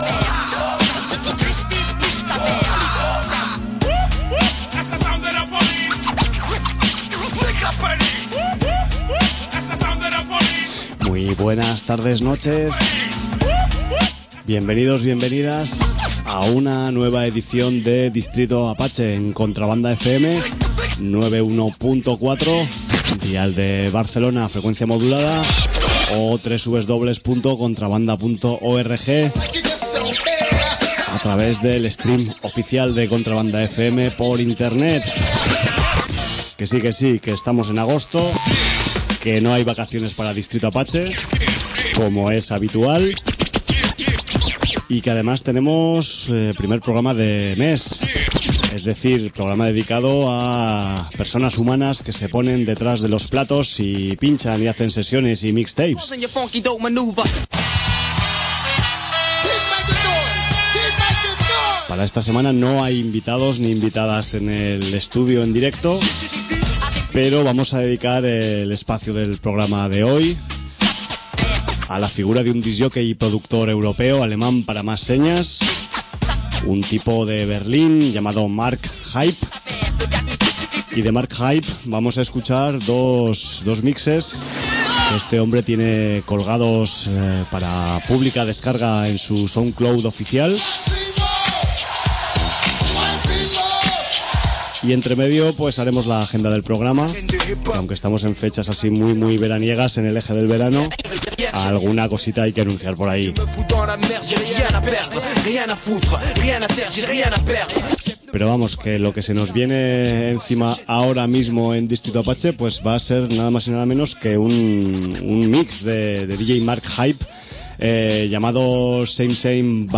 rítmico trabajo a los platos
y escuchamos 2 mixes de su cosecha de Soundcloud